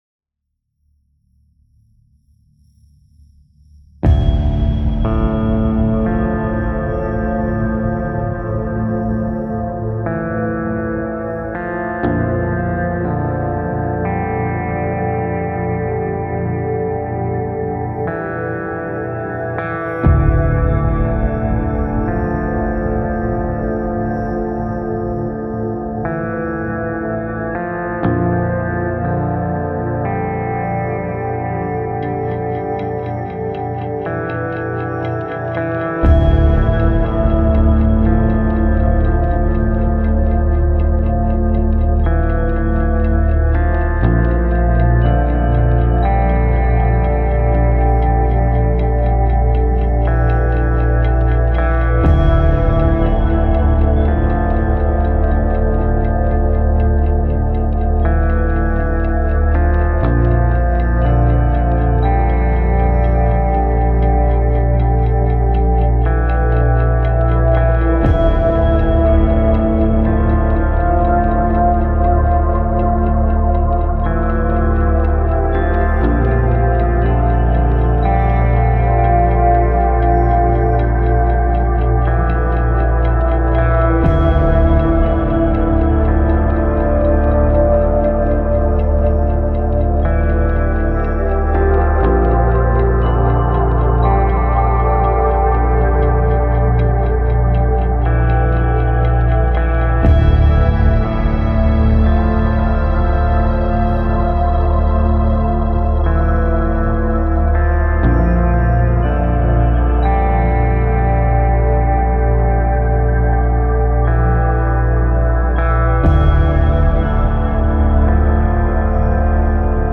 8-string metal guitar for Kontakt
Audio Demos